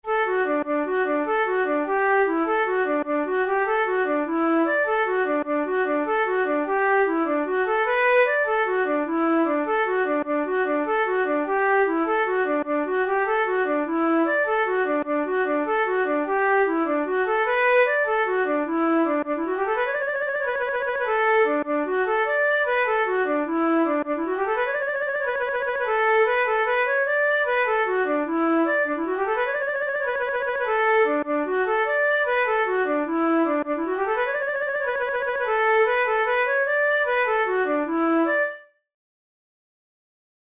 InstrumentationFlute solo
KeyD major
Time signature6/8
Tempo100 BPM
Jigs, Traditional/Folk
Traditional Irish jig
This lively jig is taken from Francis O'Neill's collection The Dance Music of Ireland, published in Chicago in 1907.